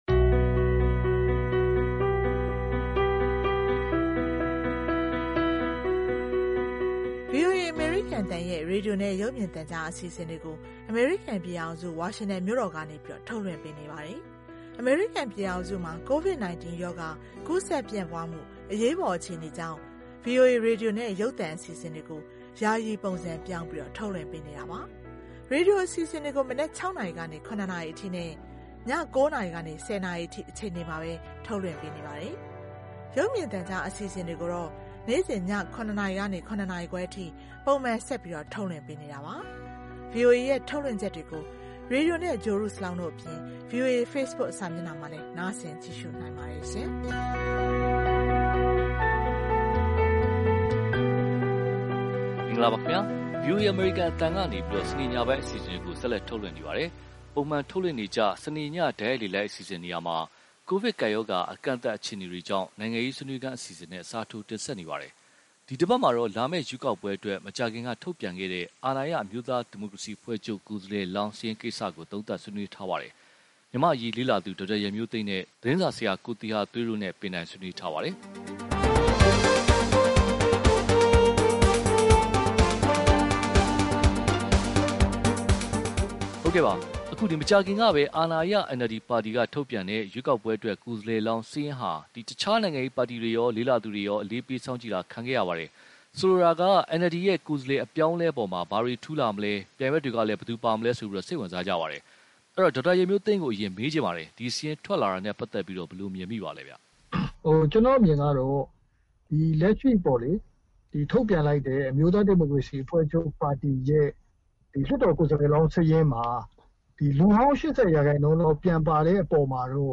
(zawgyi/unicode) ဇူလှိုင် ၂၅၊ ၂၀၂၀။ ပုံမှန်ထုတ်လွင့်နေကြ စနေည တိုက်ရိုက်လေလှိုင်းအစီအစဉ်နေရာမှာ ကိုဗစ်ကပ်ရောဂါအကန့်အသတ်အခြေအနေကြောင့် နိ်ုင်ငံရေးဆွေးနွေးခန်းအစီအစဉ်နဲ့ အစားထိုးတင်ဆက်နေပါတယ်။ ဒီတပတ်မှာတော့ လာမယ့်ရွေးကောက်ပွဲအတွက် မကြာခင်က ထုတ်ပြန်ခဲ့တဲ့ အာဏာရ အမျိုးသားဒီမိုကရေစီအဖွဲ့ချုပ်...